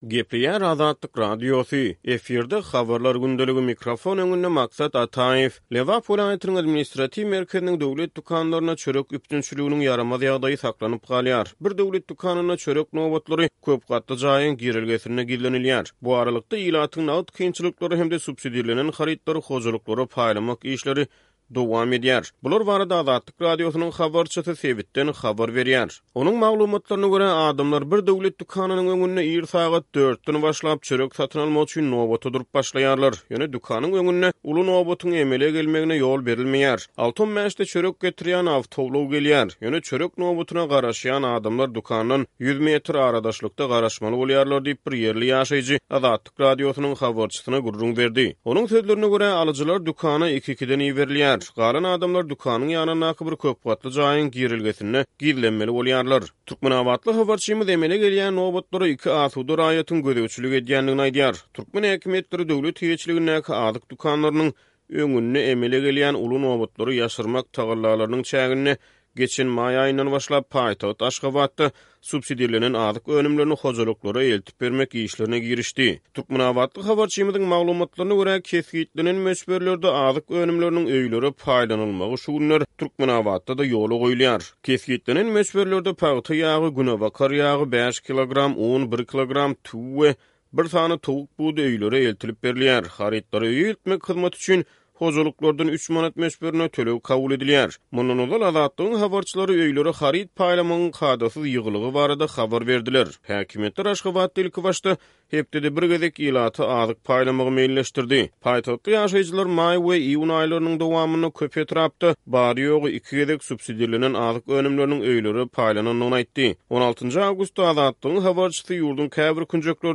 Bular barada Azatlyk Radiosynyň habarçysy sebitden habar berýär.